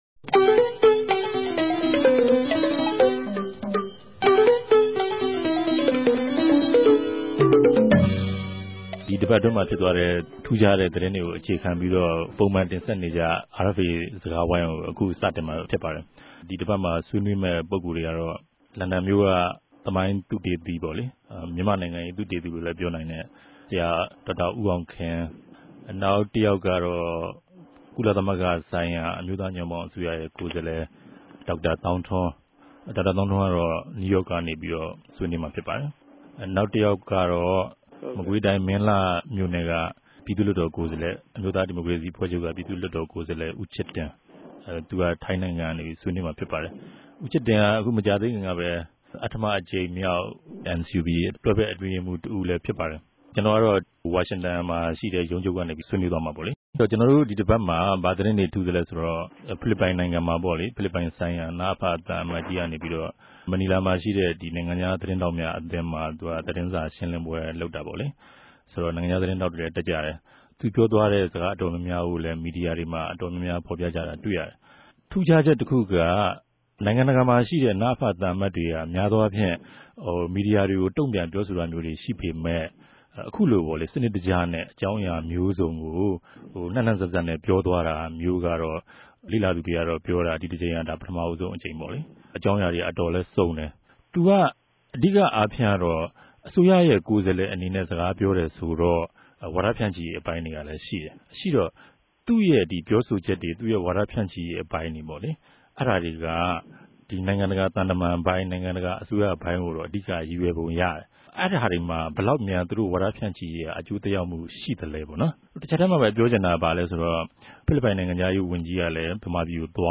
တပတ်အတြင်းသတင်းသုံးသပ်ခဵက် စကားဝိုင်း (၂၀၀၆ ုသဂုတ်လ ၁၉ရက်)